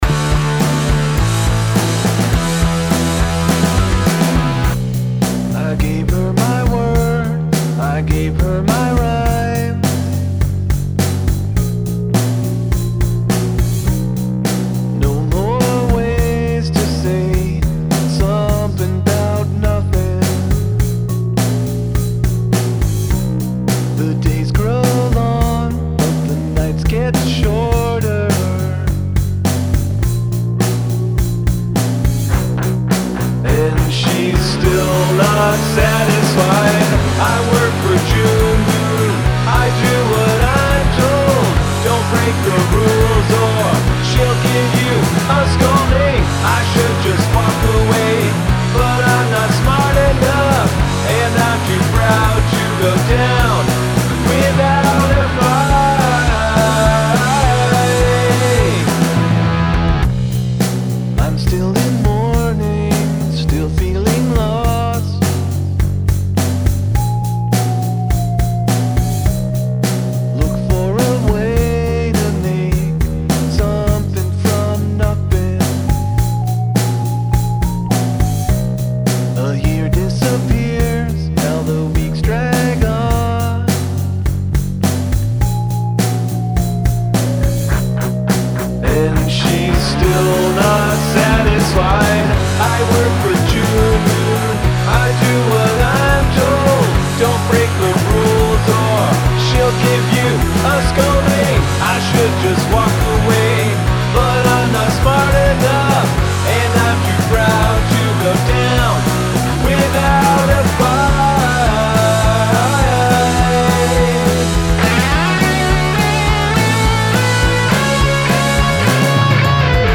Still, a very enjoyable slice of alt-rock.